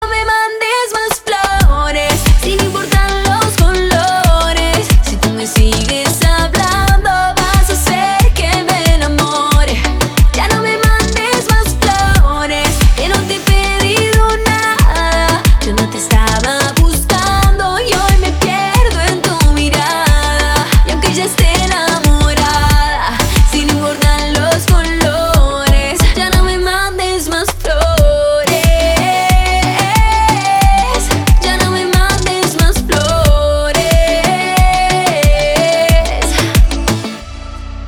• Качество: 320, Stereo
поп
ритмичные
красивый женский голос